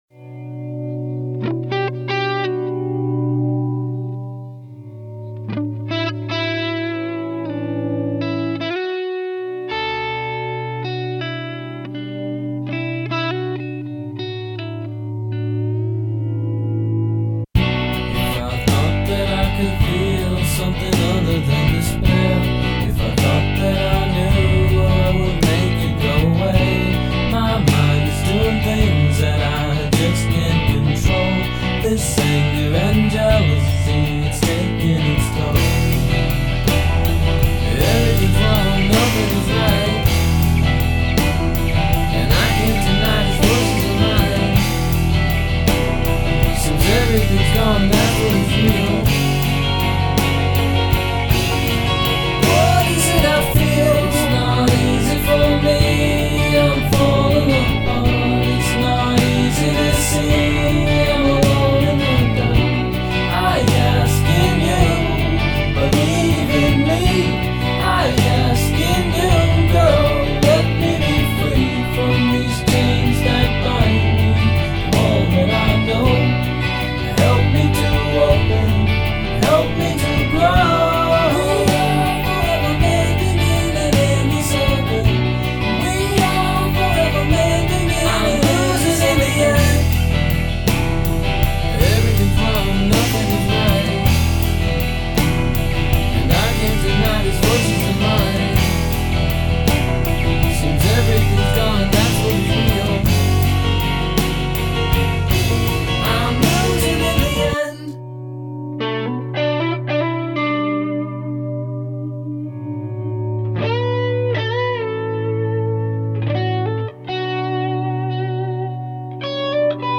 Finger Picking